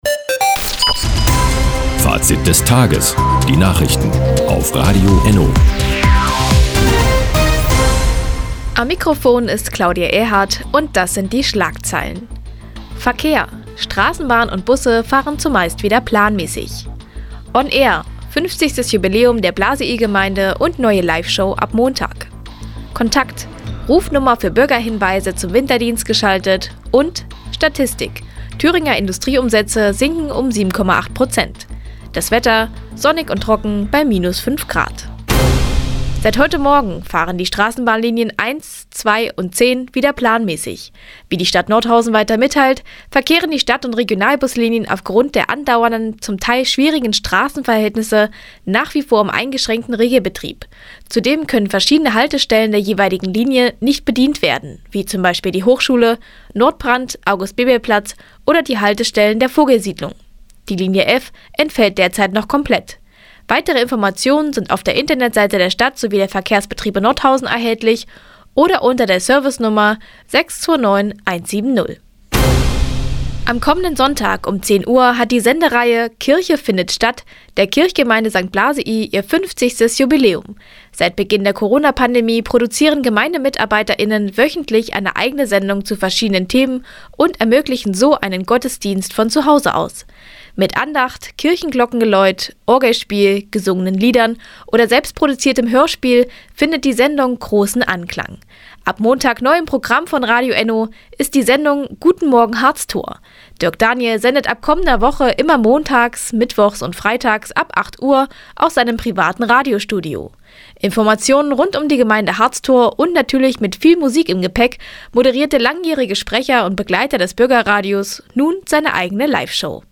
Die tägliche Nachrichtensendung ist jetzt hier zu hören.